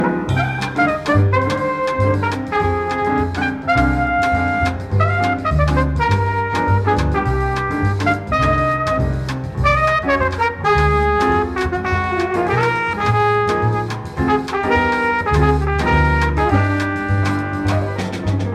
Latin (Bossa)